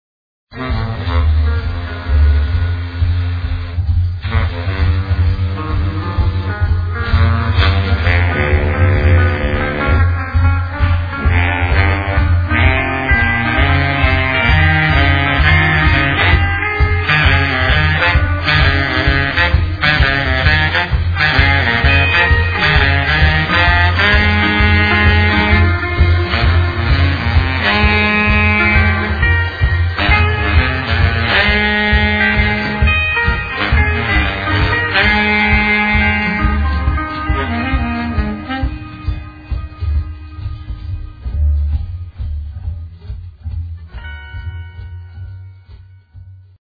freejazz
baritone saxophone
el. doublebass
drums
saw, midi guitar, el. guitar). Live from Brno [2002].